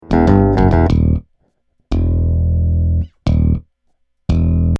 标签： 贝斯 电子 女声 时髦 循环 男声 合成器 技术